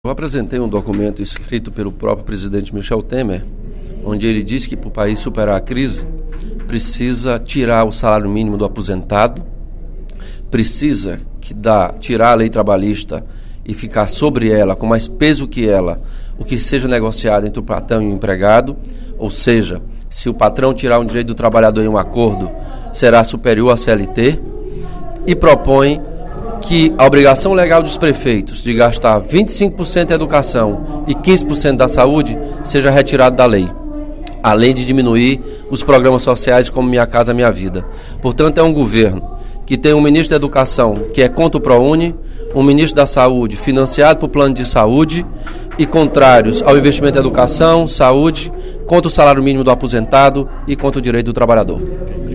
O deputado Elmano Freitas (PT) fez pronunciamento, durante o primeiro expediente da sessão plenária desta quarta-feira (18/05), para avaliar e criticar o programa de governo do PMDB, denominado de "Ponte para o Futuro", que foi produzido em outubro de 2015.